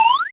jump1.wav